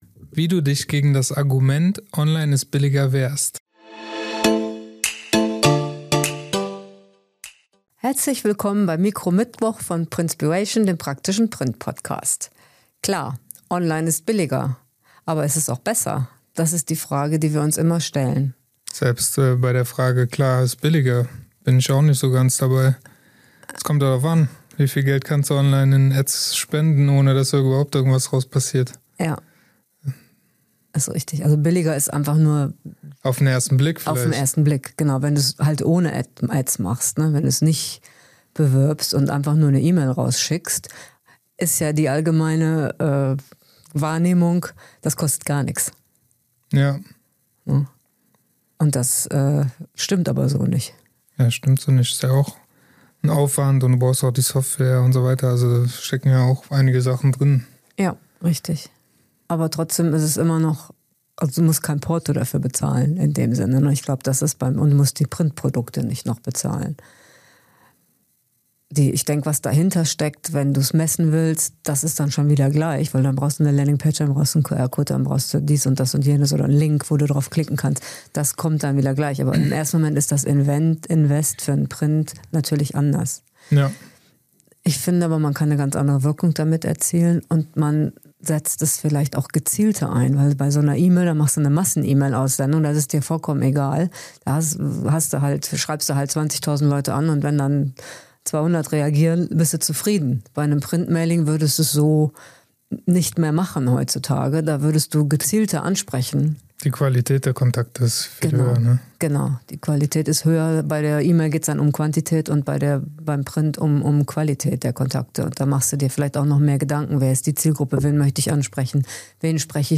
✅Mikro-Mittwoch - Snackable Content - Eine Frage, eine Antwort